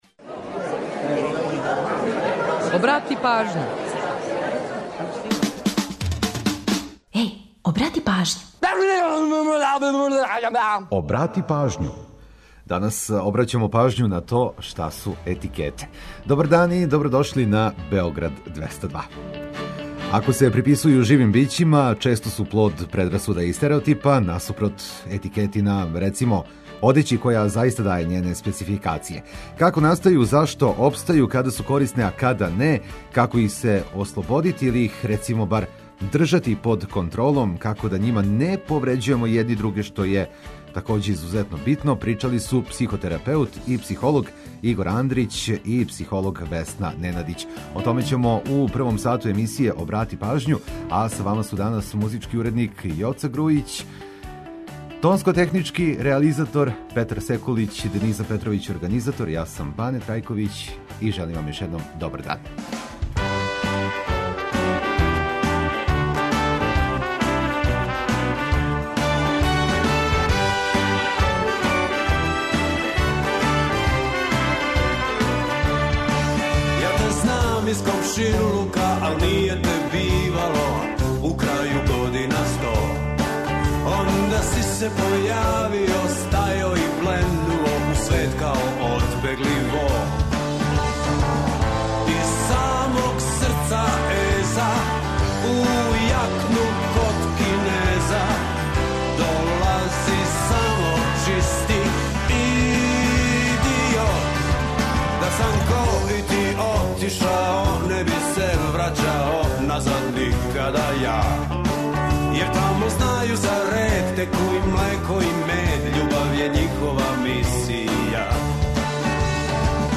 Технологијом и дигиталним светом бавићемо се у рубрици „Шта каже мрежа”, где говоримо о новој опцији на Фејсбуку која је хуманитарног карактера. Корисне информације и омиљена музика су обавезни детаљ.